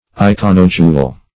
Search Result for " iconodule" : The Collaborative International Dictionary of English v.0.48: Iconodule \I*con"o*dule\, Iconodulist \I*con"o*du`list\, n. [Gr. e'ikw`n an image + ? a slave.]